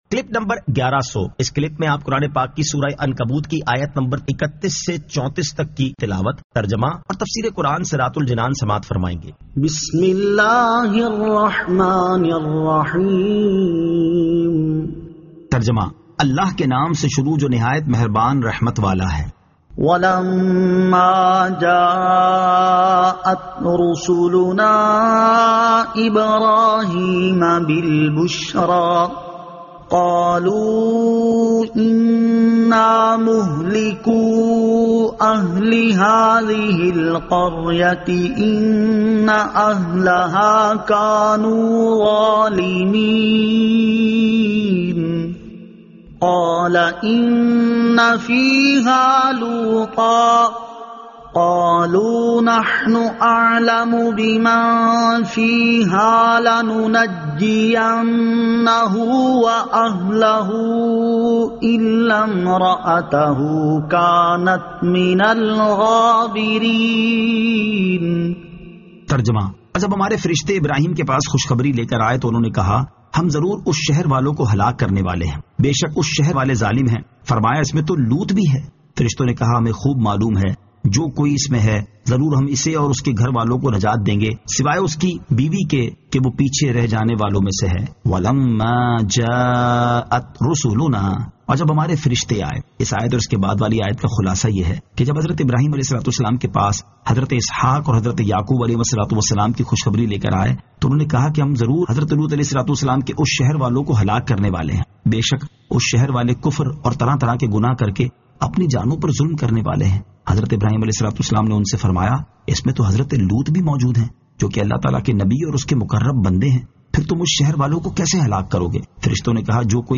Surah Al-Ankabut 31 To 34 Tilawat , Tarjama , Tafseer